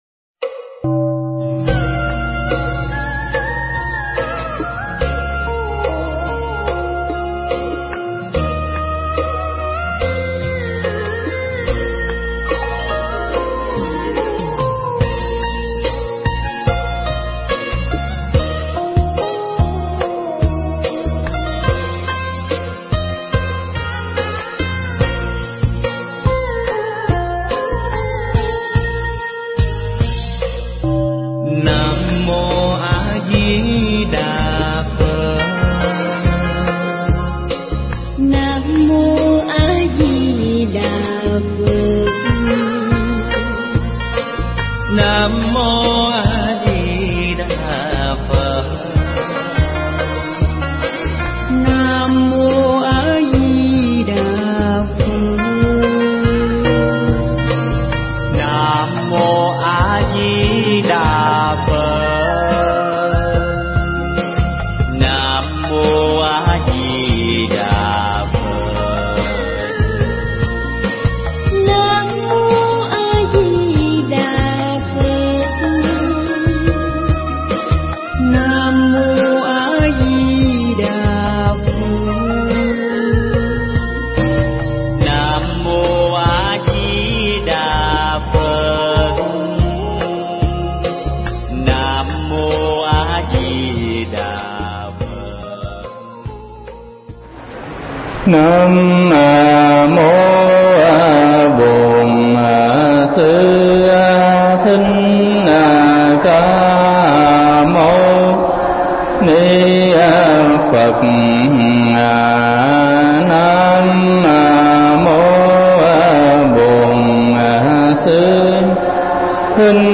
Mp3 Thuyết Pháp Sự Tích Phật A Di Đà và Cõi Tây Phương
giảng tại Chùa Giác Hạnh